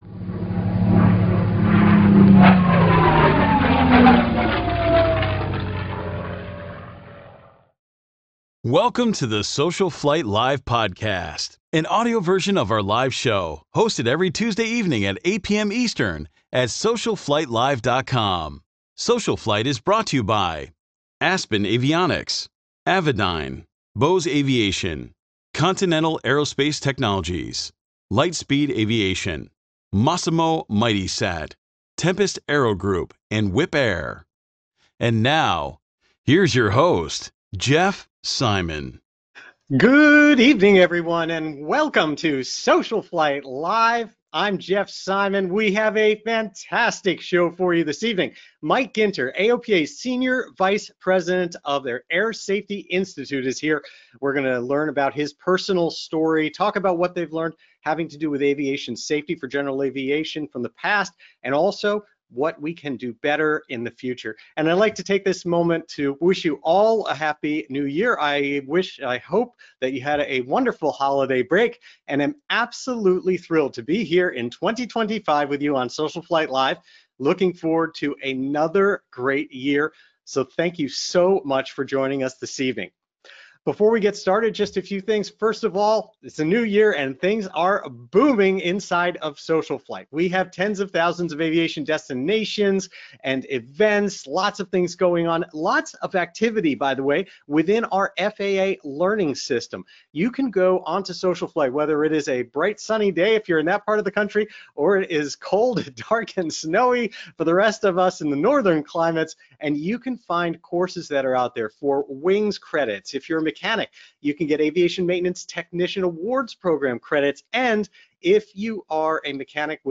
“SocialFlight Live!” is a live broadcast dedicated to supporting General Aviation pilots and enthusiasts during these challenging times.